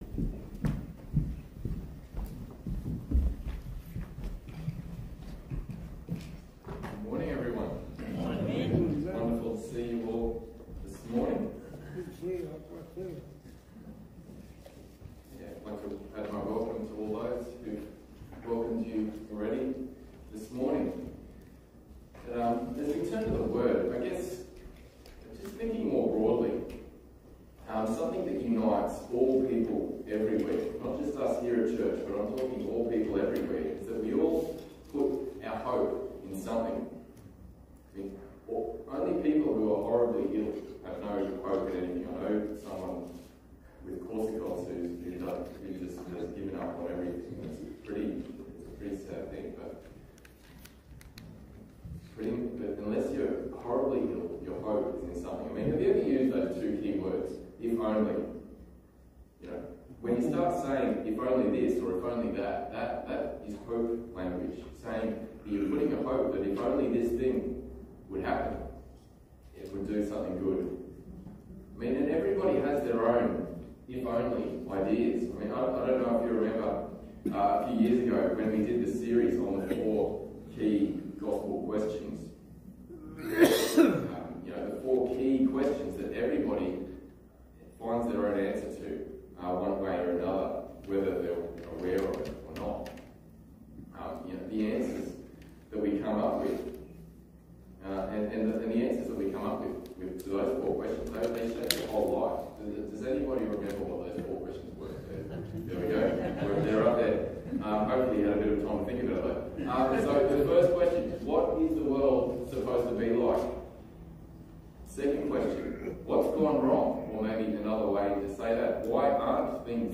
Passage: 1 Peter 1:3-12 Service Type: Sunday Morning